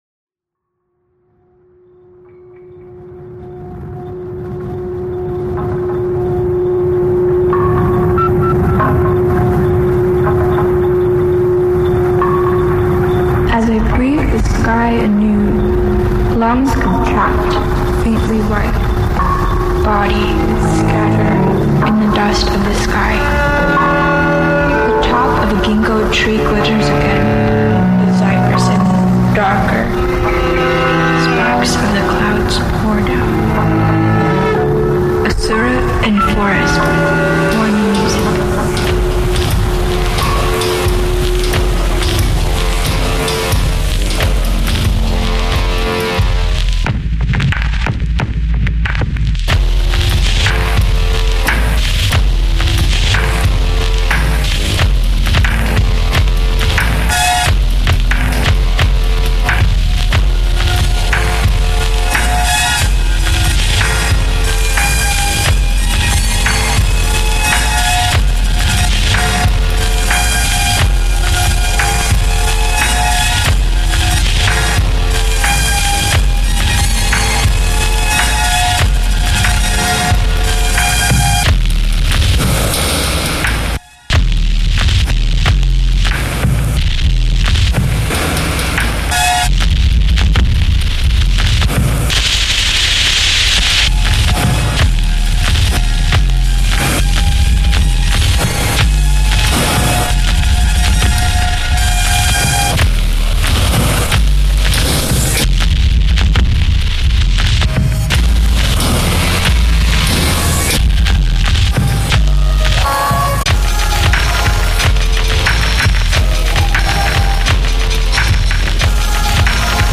Beats Indie